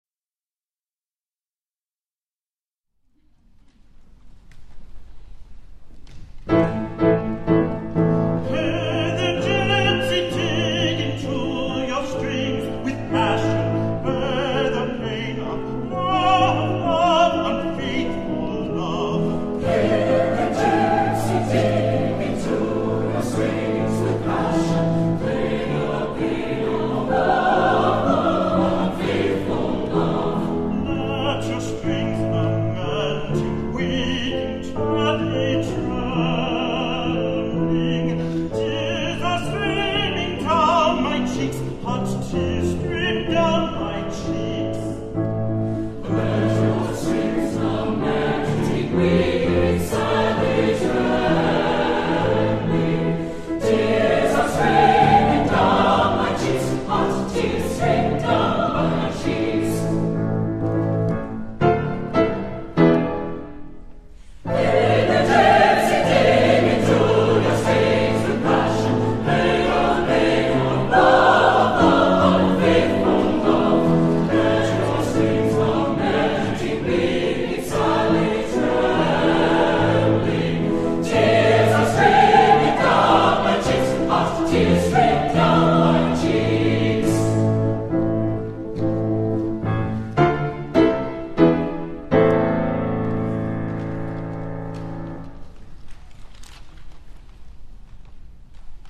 Accompaniment:      With Piano
Music Category:      Choral
Performed at the Choral Arts Symposium